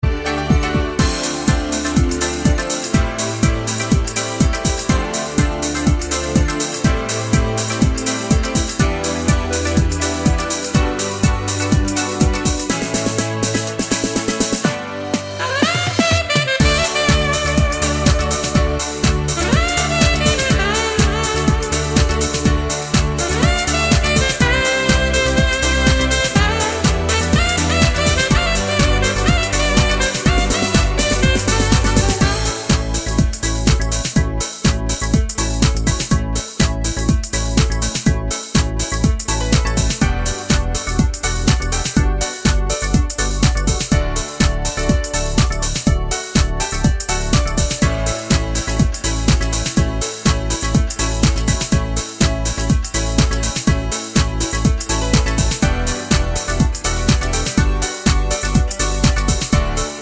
Minus Sax Pop